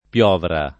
piovra [ p L0 vra ]